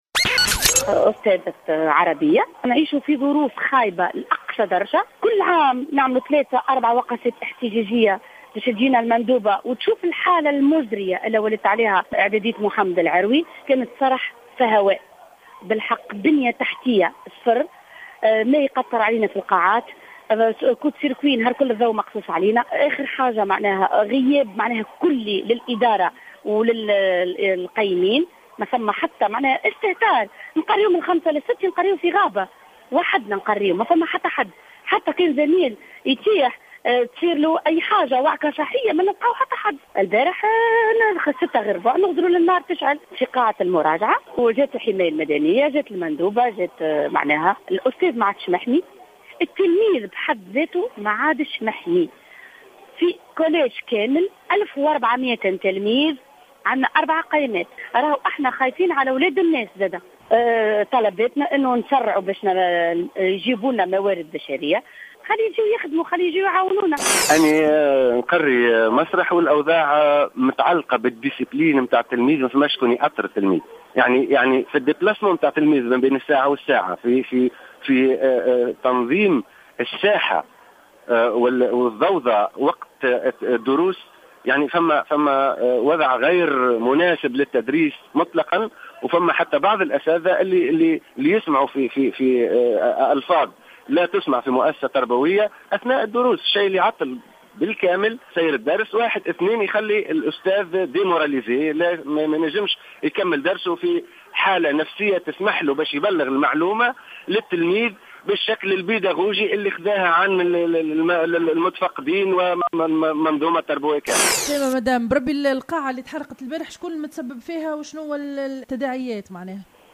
Une enseignante du collège a rapporté au micro de Jawhara Fm avoir aperçu des feux se dégager de la salle en question jeudi vers 18heures et soupçonne que des élèves du collège auraient incendié la salle.
Témoignages des enseignants t√©l√©charger partager sur